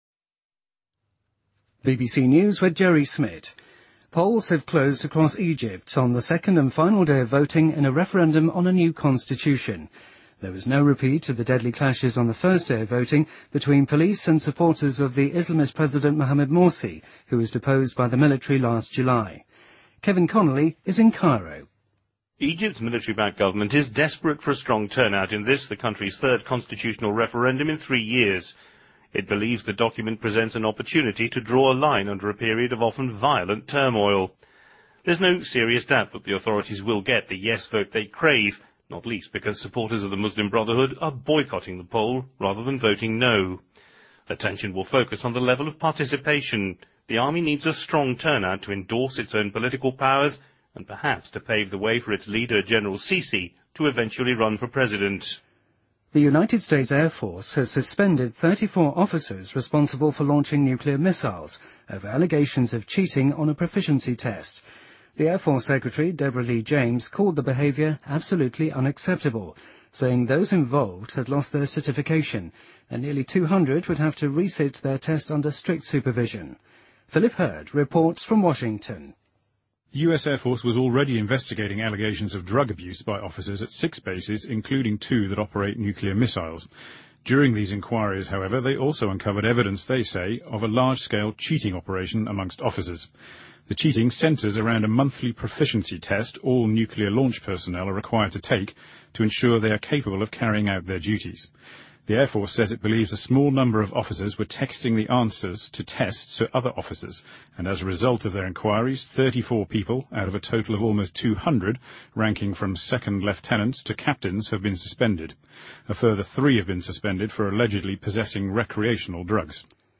BBC news,2014-01-16